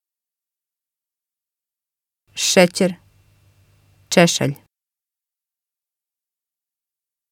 Je krijgt twee woorden te horen. Kies hieronder de spelling van het eerste woord.